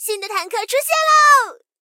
SU-76建造完成提醒语音.OGG